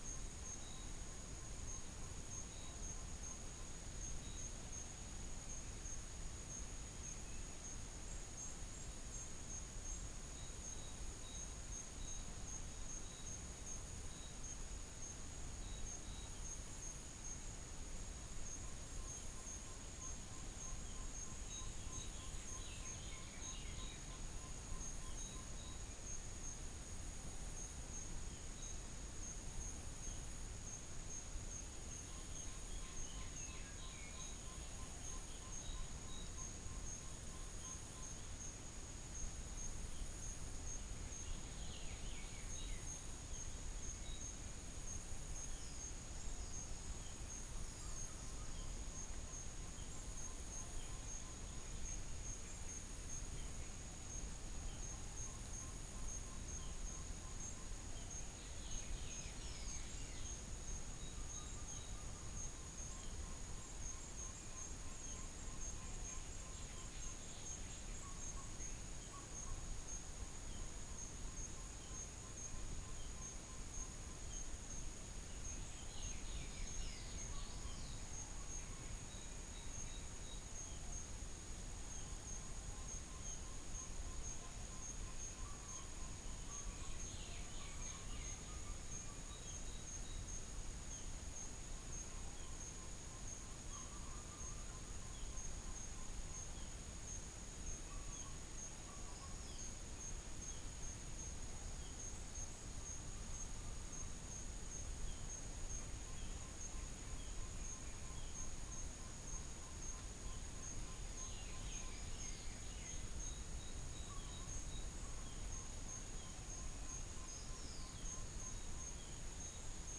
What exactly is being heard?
Stachyris nigricollis Psilopogon duvaucelii Hypothymis azurea